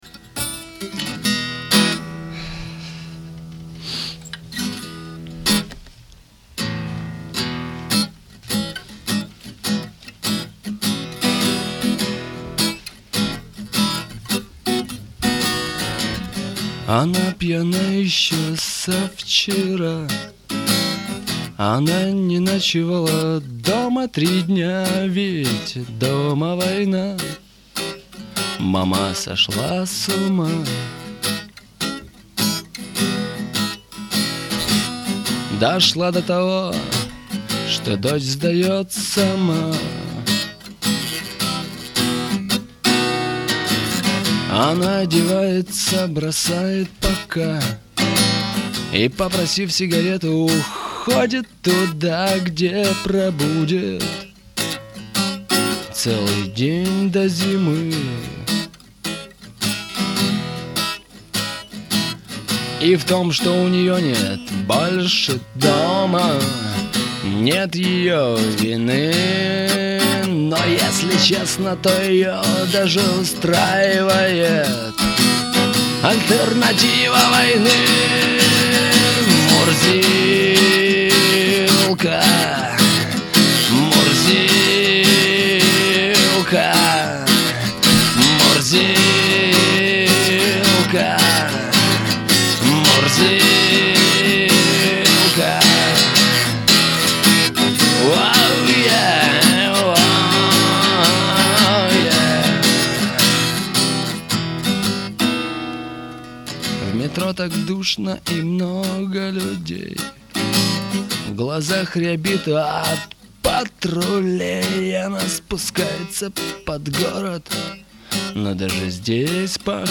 вокал, гитара